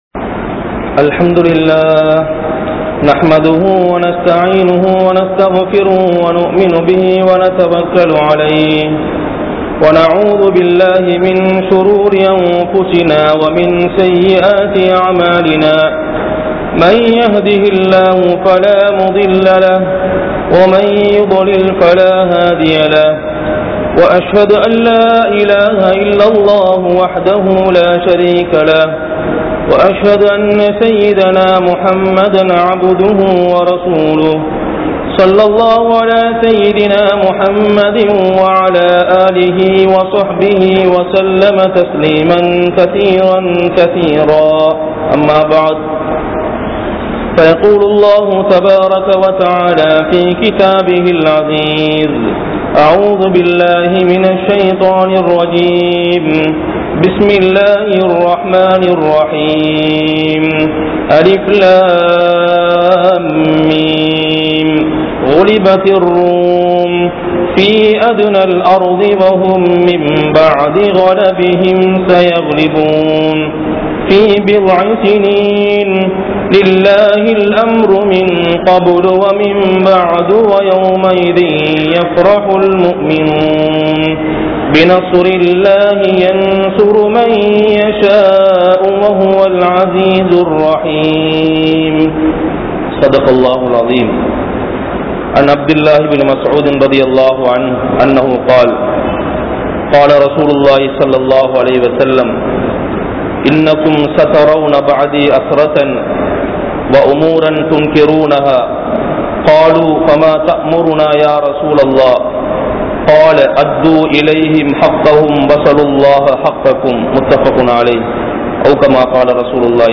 Allah`vai Nambugal (அல்லாஹ்வை நம்புங்கள்) | Audio Bayans | All Ceylon Muslim Youth Community | Addalaichenai
Kollupitty Jumua Masjith